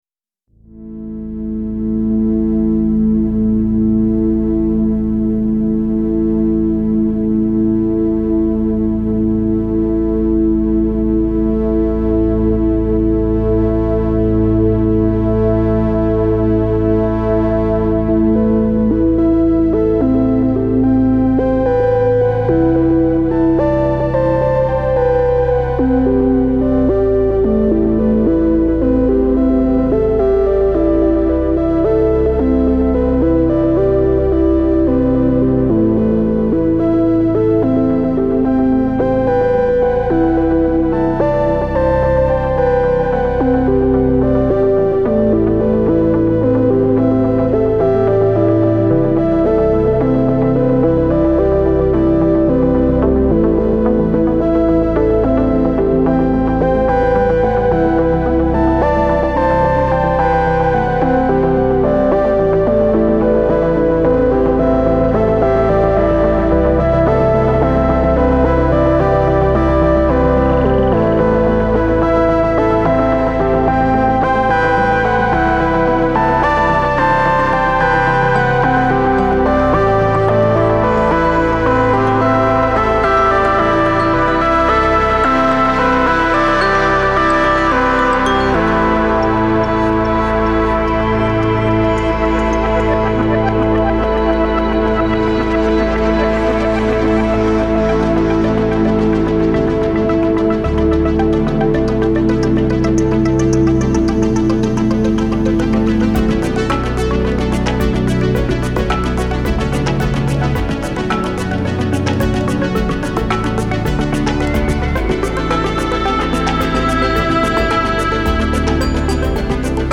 Genre : Ambient